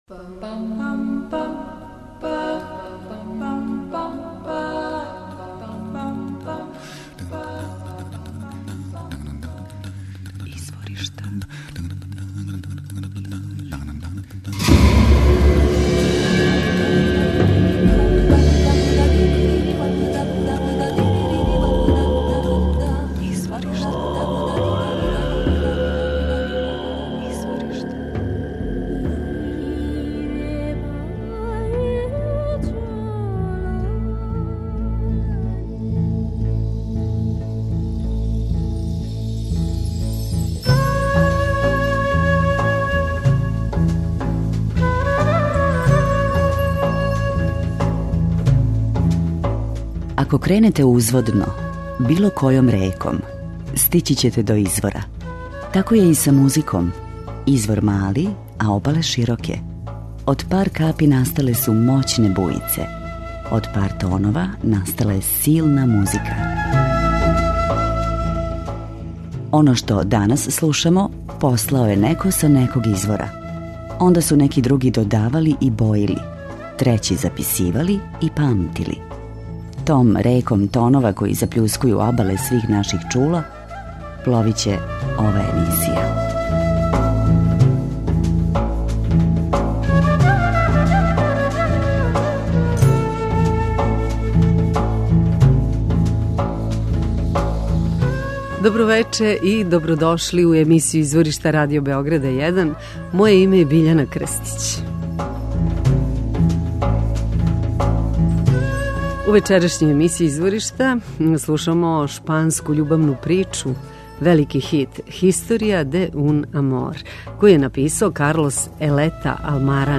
У две речи: World Music.